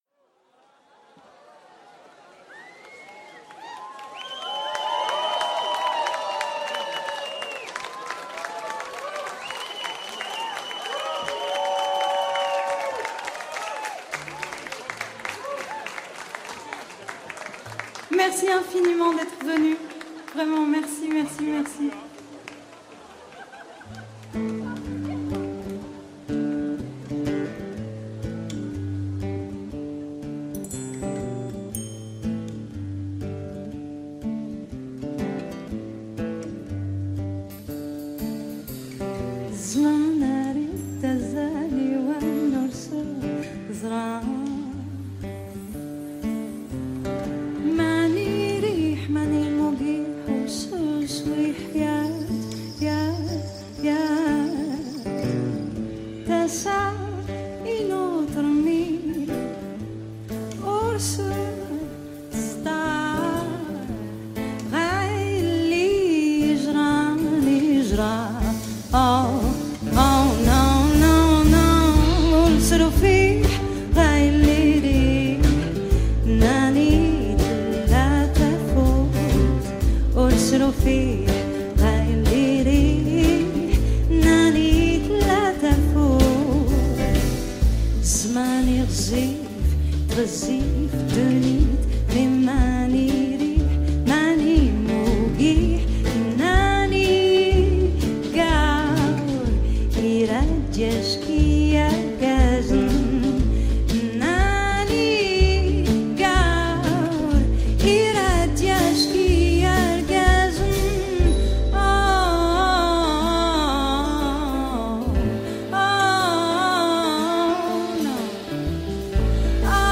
Live in Concert from Paris
Live at Musée Picasso – Paris
to close the weekend on an ethereal note.